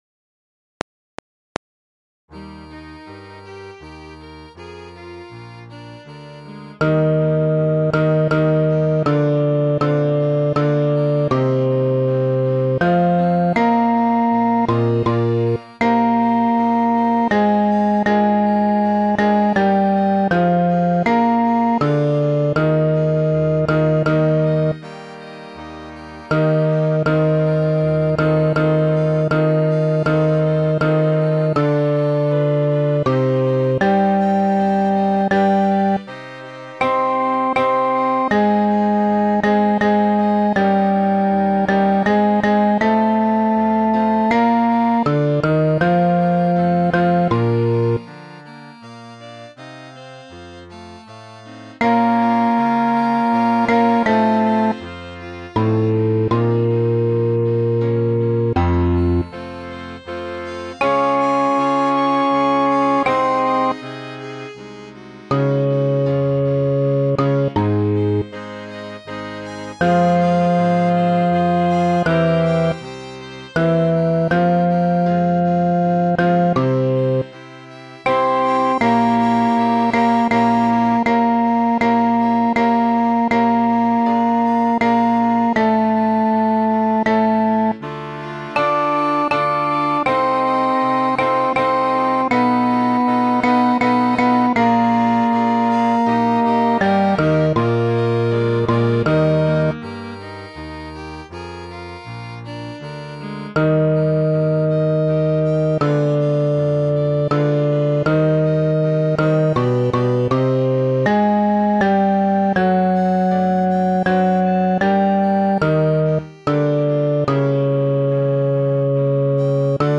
(fade-out from bar-55)    SOP  ALT  TEN